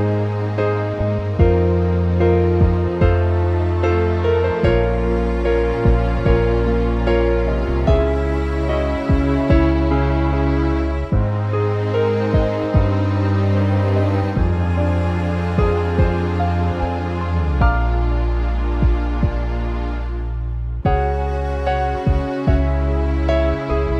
no Backing Vocals Soundtracks 2:58 Buy £1.50